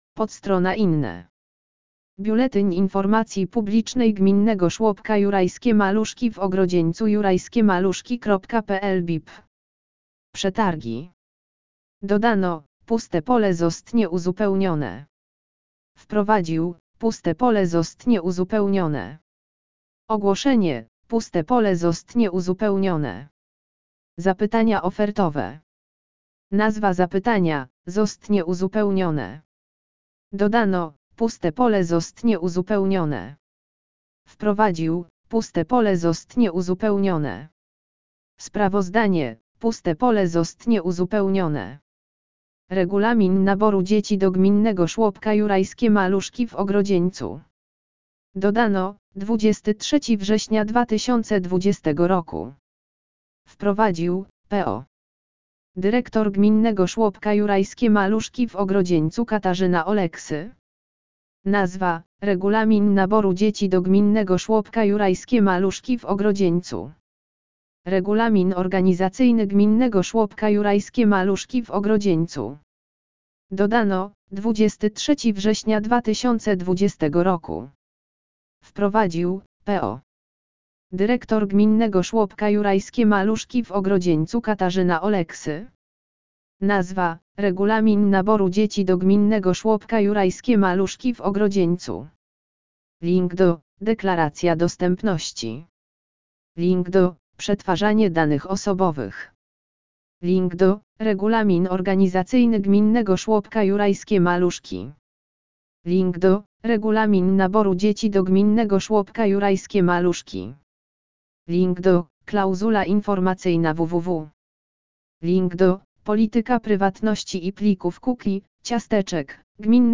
lektor-BIP-INNE-kn7koy4p.mp3